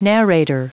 Prononciation du mot narrator en anglais (fichier audio)
Prononciation du mot : narrator